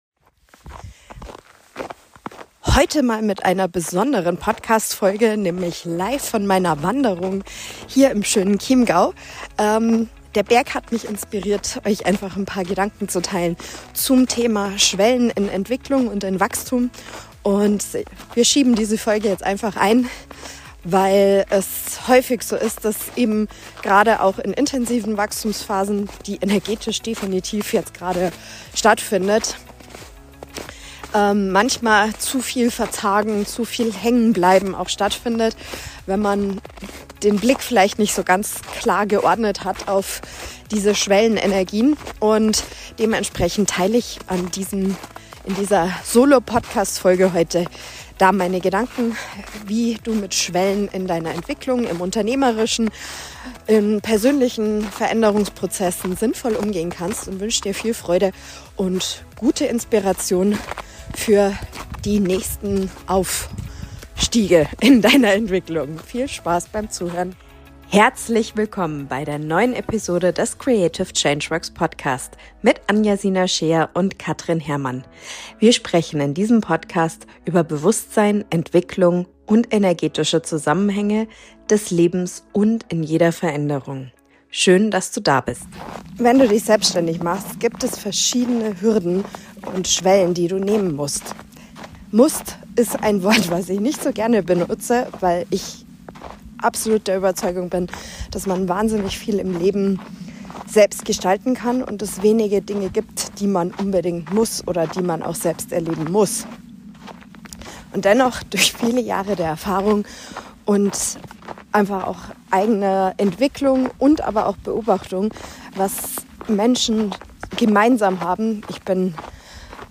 In dieser Solo-Folge spreche ich über typische Schwellen, die wir in Entwicklungsprozessen erleben: vor dem Anfang, kurz vor der Erfüllung und in der Integrationsphase danach.